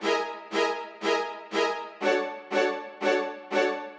Index of /musicradar/gangster-sting-samples/120bpm Loops
GS_Viols_120-E.wav